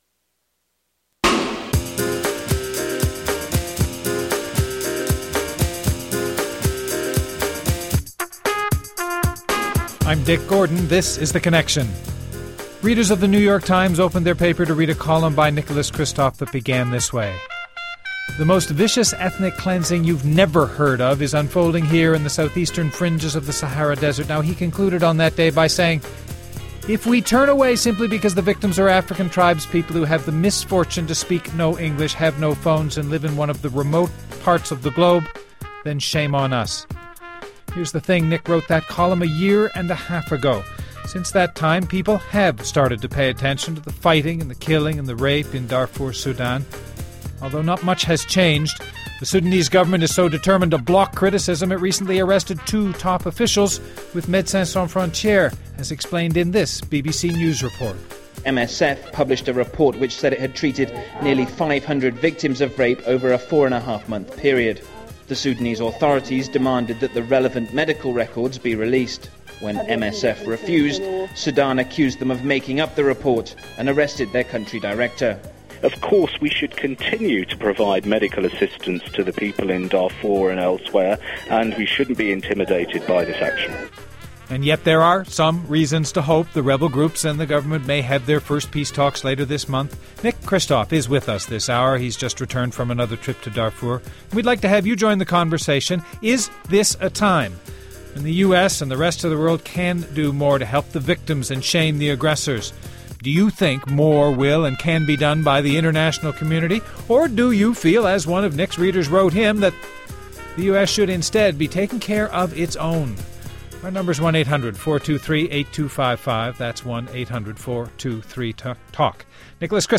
Guests: Nicholas Kristof, Op-Ed columnist for The New York Times.